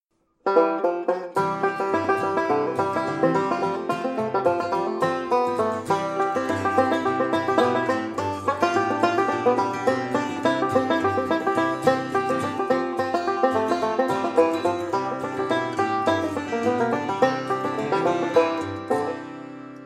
on the 5-string banjo